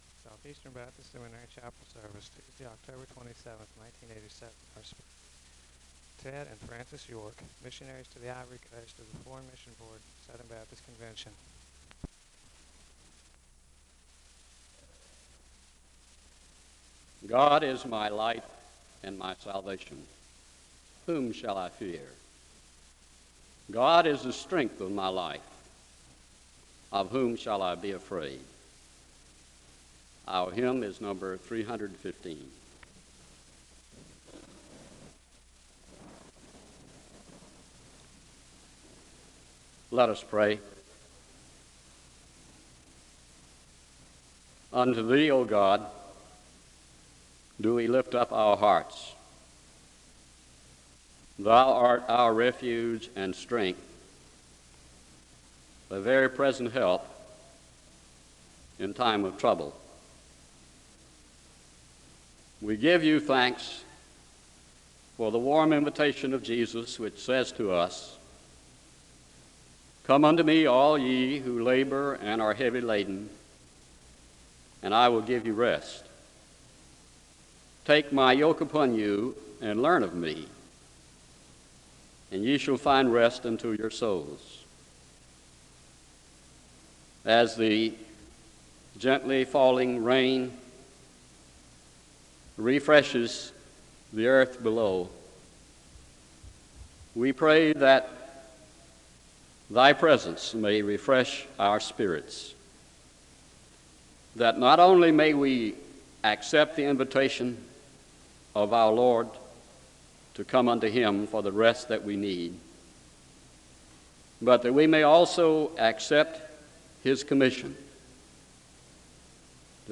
The service begins with a Scripture reading and a moment of prayer (0:00-2:55).
The service closes with a benediction (18:54-19:27).
SEBTS Chapel and Special Event Recordings